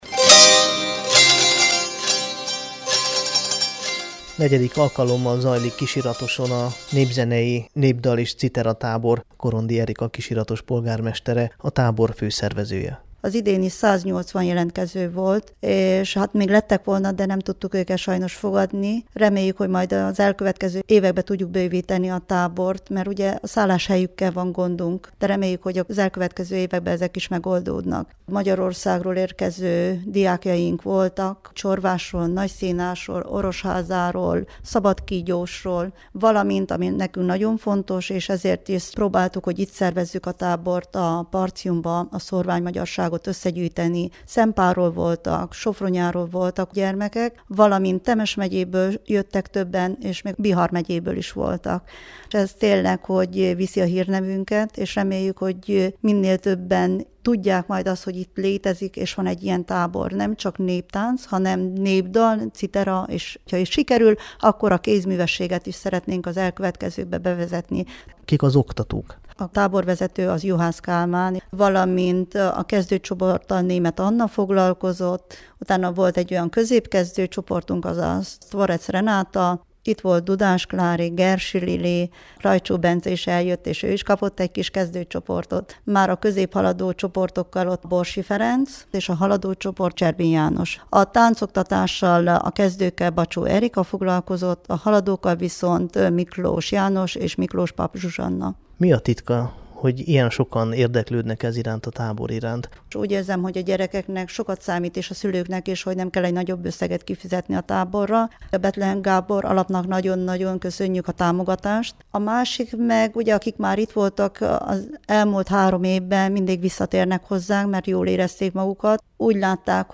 A napi két próba mellett számos szabadidős tevékenységen is részt vehettek az anyaországból és az Arad, Temes és Bihar megyéből érkezők, akik szombat este a kisiratosi kultúrházban gála keretében mutatták be a tanultakat.
A riportot a képgaléria végén hallgathatja meg.
4-ik_nepzenei_tabor_kisiratoson_2016.mp3